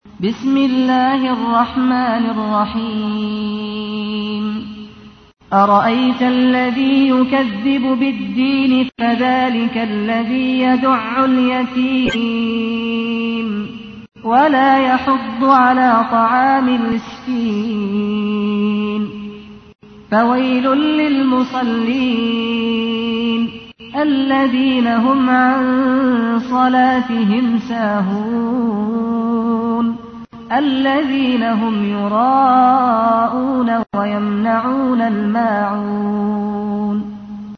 تحميل : 107. سورة الماعون / القارئ سعد الغامدي / القرآن الكريم / موقع يا حسين